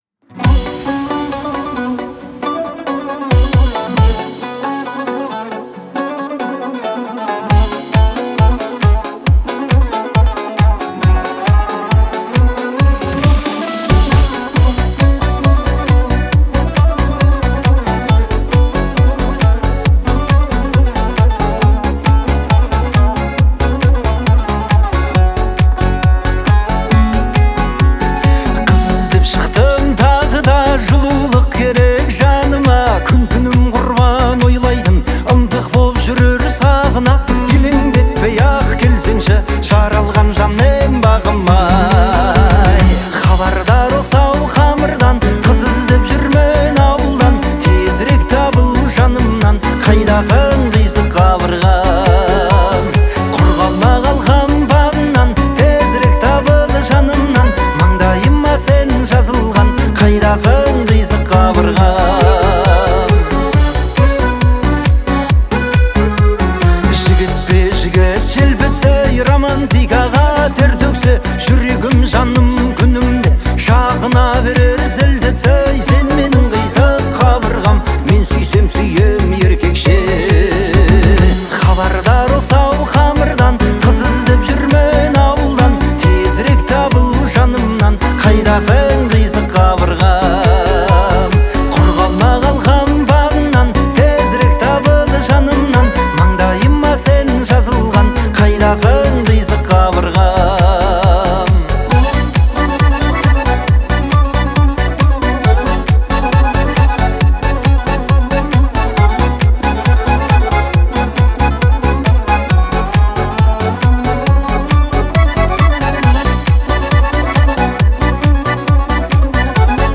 трогательный пример казахской поп-музыки